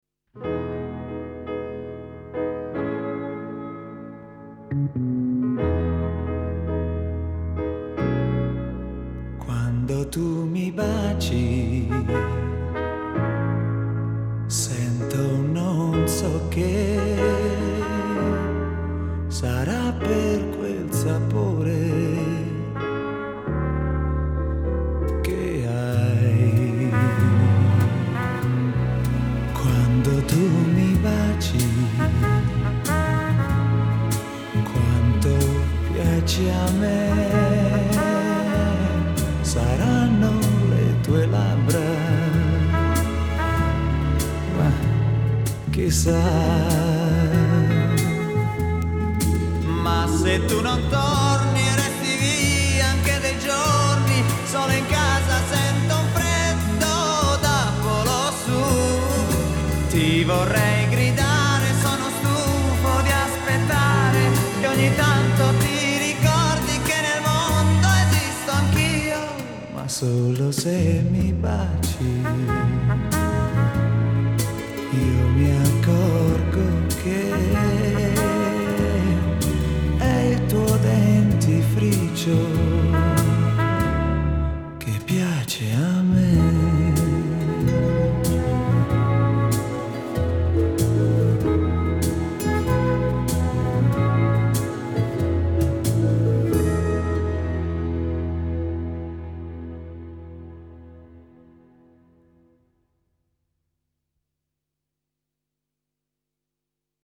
Genre: Pop, Rock